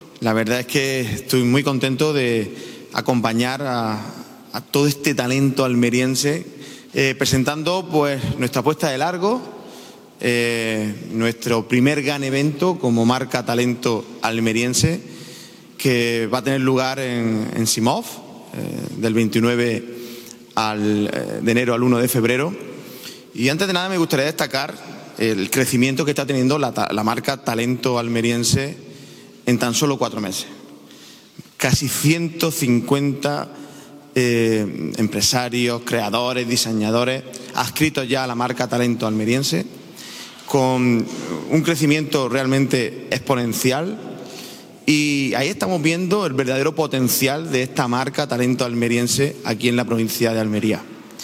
Durante el acto, el presidente de la Diputación de Almería, José Antonio García Alcaina, ha destacado el crecimiento “realmente exponencial” que ha experimentado la marca Talento Almeriense en apenas cuatro meses, con cerca de 150 empresarios, creadores y diseñadores ya vinculados al proyecto.